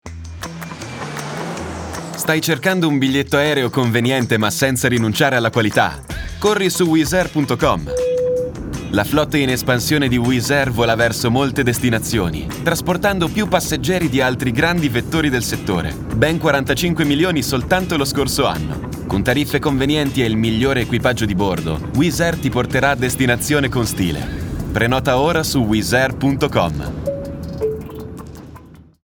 Male
Confident, Engaging, Friendly, Natural, Versatile, Corporate, Deep, Young
Microphone: SE Electronics 2200A / Shure MV7